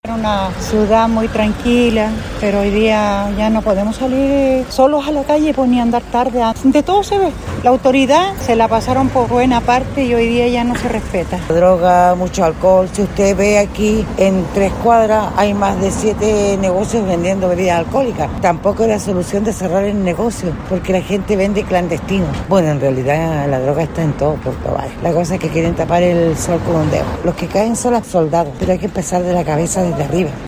En calle Colón, por donde se activó un intenso operativo de control de identidad y vehicular que se extendió por casi todo Puerto Chico, La Radio conversó con algunas puertovarinas, quienes relataron cómo su comuna ha cambiado.